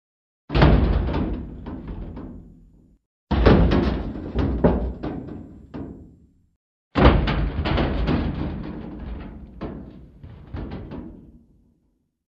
Крышка мусорного бака